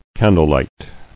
[can·dle·light]